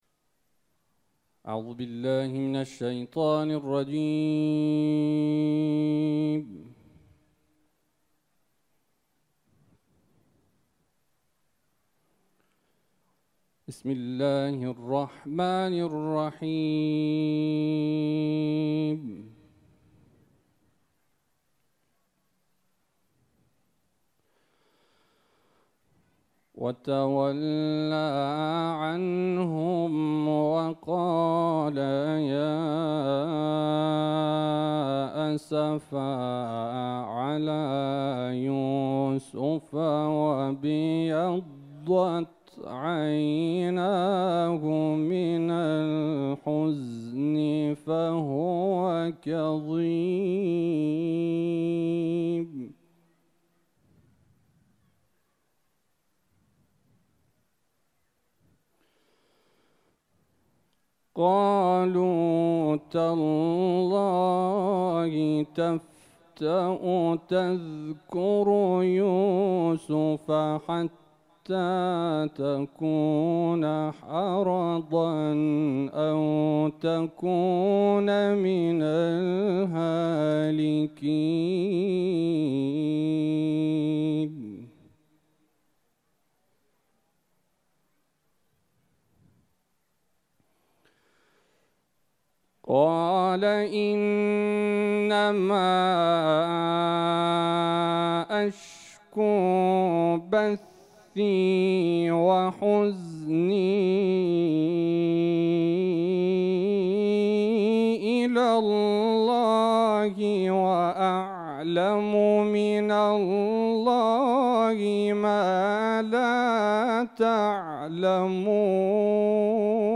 کرسی تلاوت موعد اذانگاهی مغرب سه‌شنبه‌ و پنجشنبه این هفته با حضور استادان و قاریان ممتاز در مسجد مقدس جمکران و مسجد جامع لویزان برگزار می‌شود.